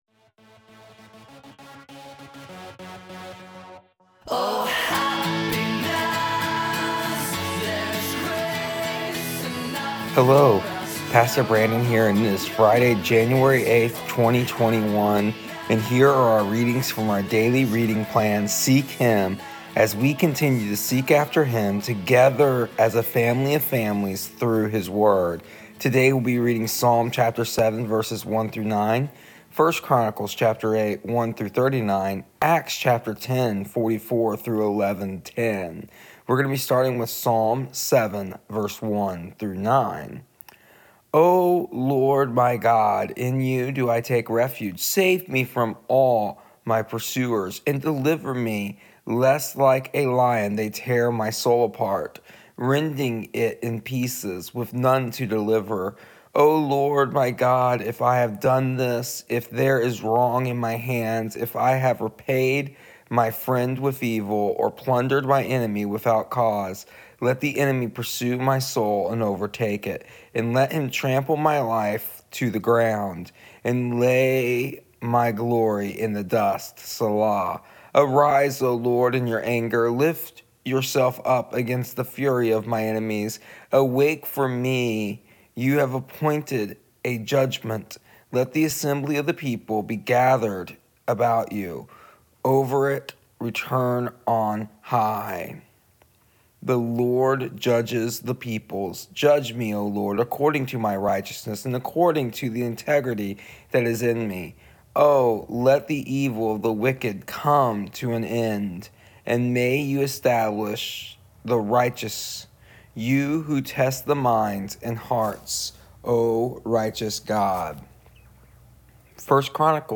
Here is the audio version of our daily readings from our daily reading plan Seek Him for January 8th, 2021.